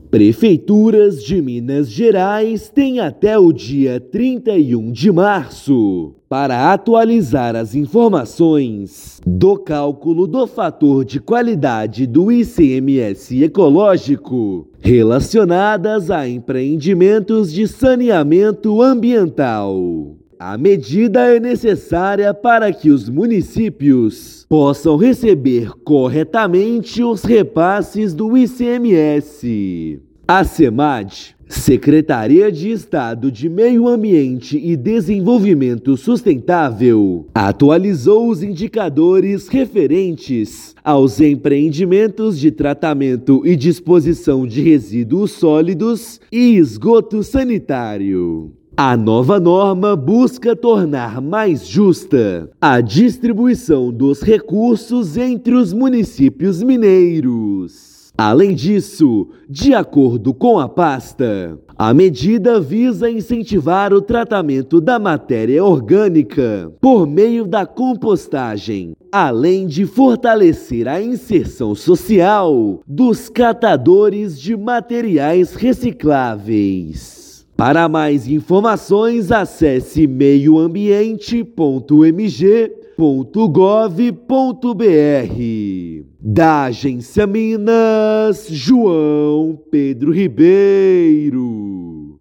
[RÁDIO] Municípios mineiros têm até 31/3 para atualizar indicadores do ICMS Ecológico ligados ao saneamento
Mudanças buscam uma distribuição ainda mais justa e eficiente dos recursos tributários arrecadados em Minas Gerais. Ouça matéria de rádio.